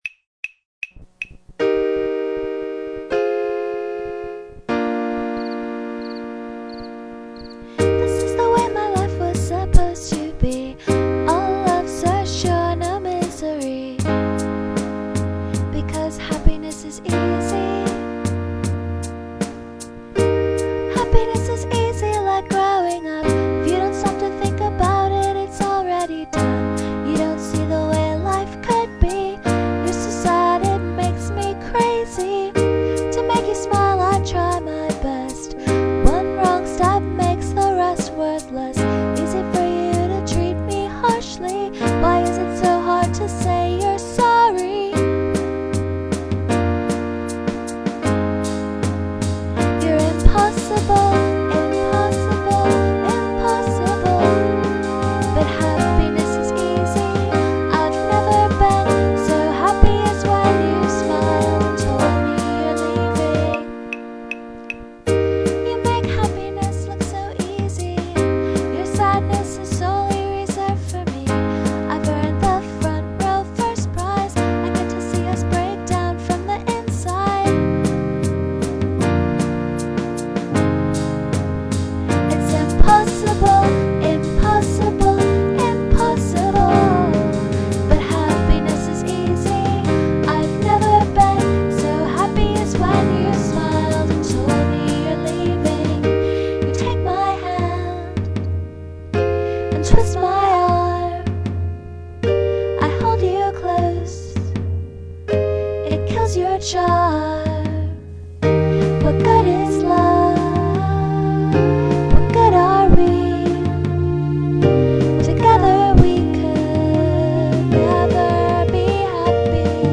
intro: e flat major, f minor, b flat major
verse: e flat major, c minor, a flat major, b flat major
this song features my yamaha over and over, plus fruityloops and some crickets.
1. this song is rad. the way you've arranged the first verse--particularly with the backing beat you've used--makes me think of ace of base. that tom/kick. so good. and ambient crickets. yes.
2. anyway, that makes me think you should just go completely mid-90s dance-pop all over this song. note that from me, this is a tremendous compliment. the most appealing part of the track to me is the unmistakable hip-hop swaggar on that first verse, I can totally see you wagging your finger at the camera in the video, backed by a posse of tough/hot-looking girls. are they wearing overalls? rollerskates? it's just got such attitude!
4. that dancey quality also makes me think the arrangement could be cleaner, more minimalistic, or at least more stingently deployed. i know nothing about dance music, but my impression is that you make it good by adding a part at a time in structurally significant moments. i think you do some of this here, and well, but i think you could use a little more restraint and really milk it. for instance, i think the arrangement gets a little out of control after the bridge, which i think causes things to get harmonically a little messy (from "what good are we?" on). this sort of "everything and the kitchen sink" arrangement from that point means that the last refrain is louder, but it isn't as powerful as the others.